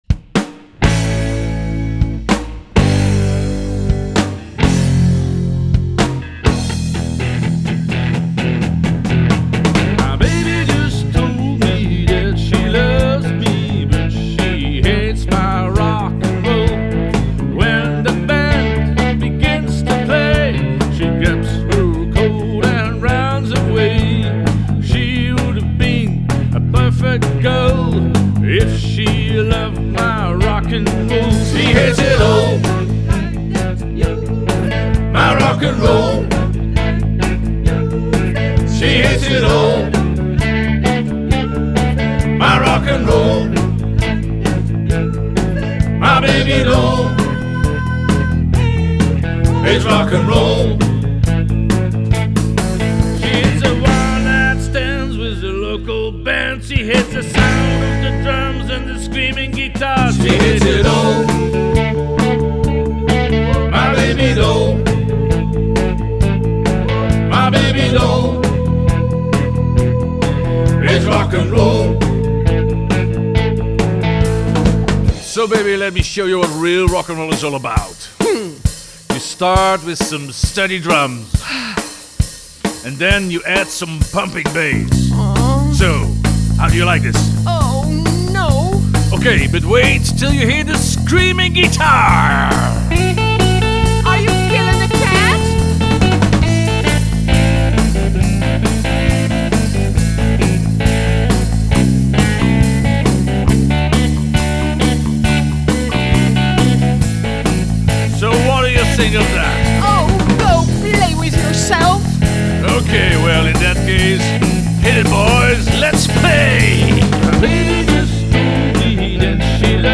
vocals
guitar
drums except on Scillies
Recorded in Studio PH14 ASE on Texel.